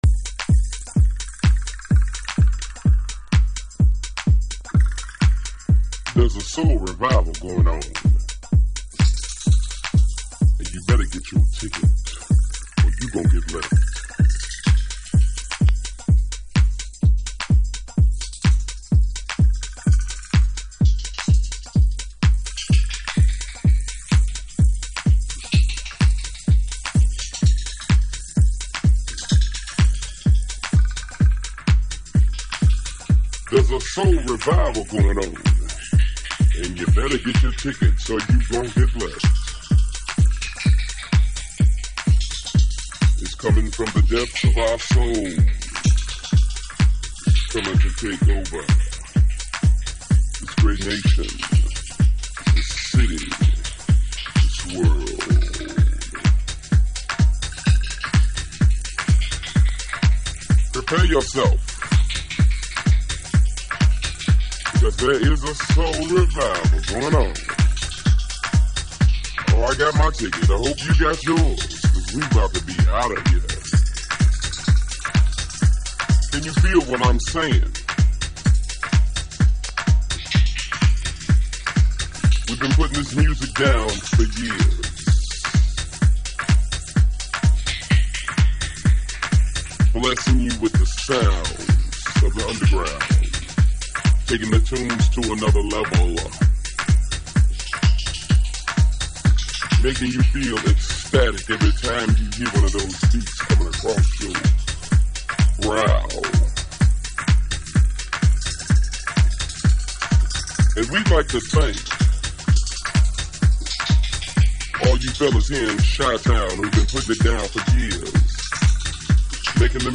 再発でも充分ありがたい、シカゴディープ名盤。
Chicago Oldschool / CDH